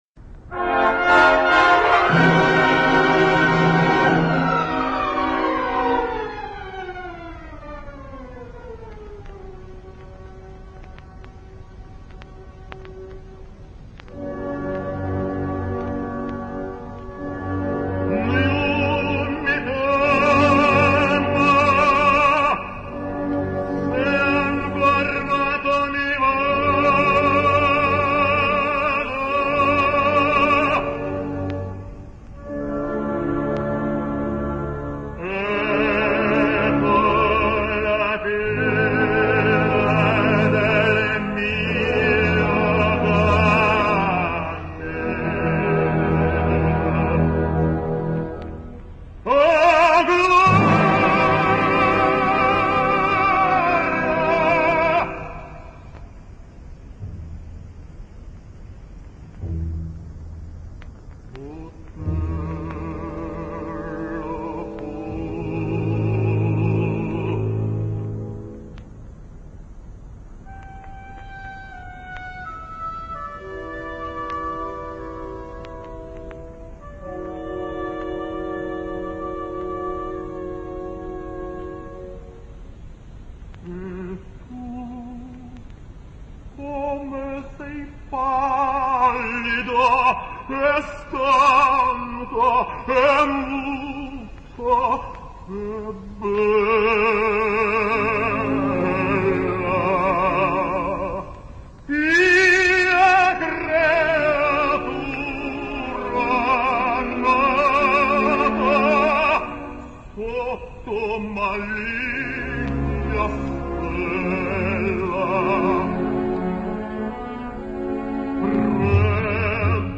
While Eastern Europe in general and Russia in particular have beyond doubt maintained quality in operatic singing for a much longer time than the rest of the world (and in some cases, they've maintained it even up to our days), it's the proof that there is no such thing as a Promised Land of opera, not even in Russia, if a positively horrible shouter and pusher like Osipov could become of the country's most famous tenors...
Vjacheslav Osipov singsOtello: